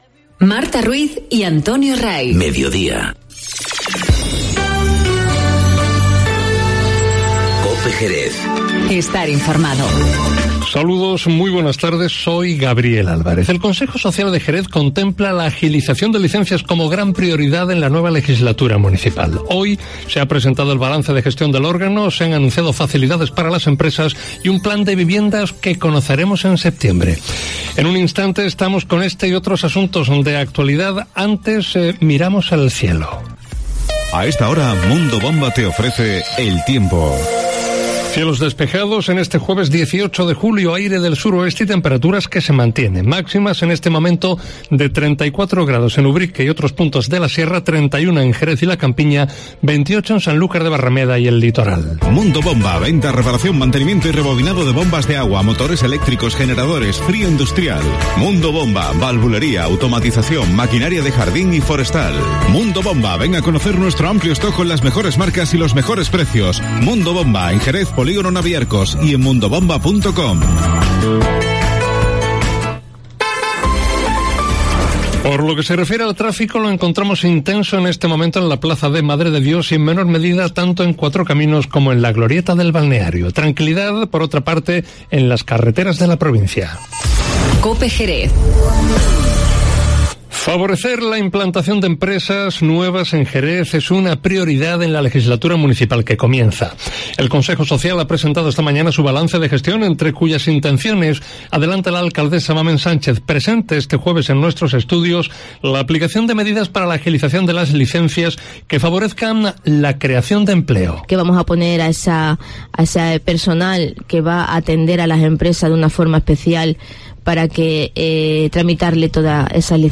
Informativo Mediodía COPE en Jerez 18-07-19